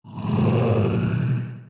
evil-deer-v1.ogg